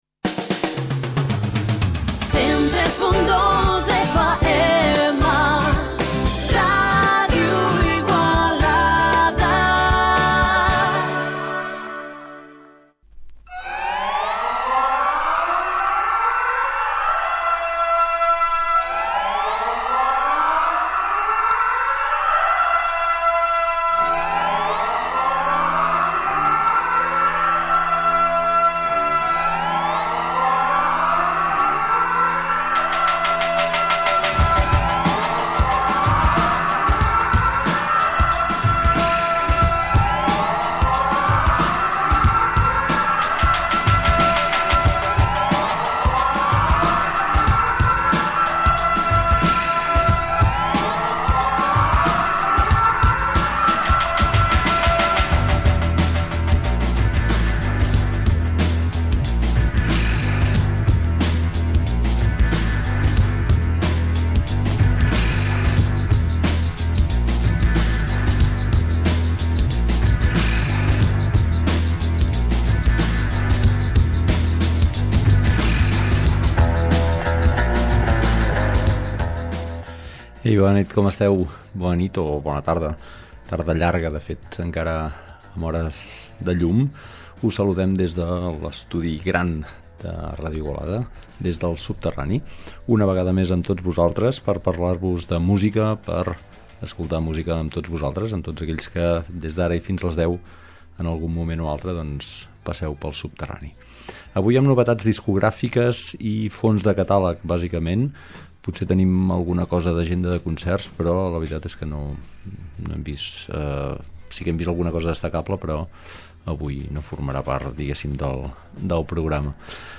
Programa de ràdio en antena des de 1993.